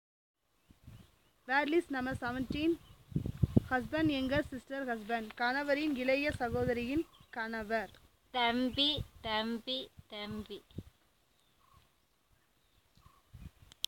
NotesThis is an elicitation of words for kinship terms, using the SPPEL Language Documentation Handbook.